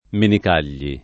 [ menik # l’l’i ]